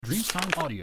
Leicht schlagen der Seite